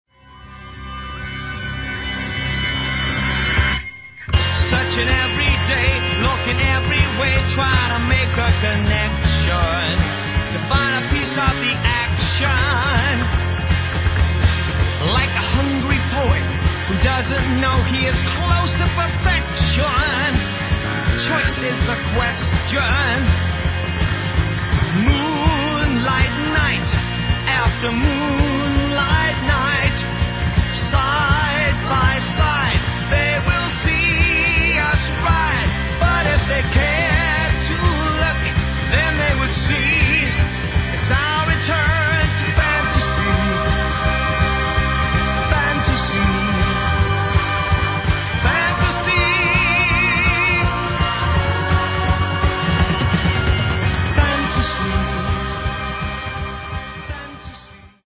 Recorded at Lansdowne and Morgan Studios,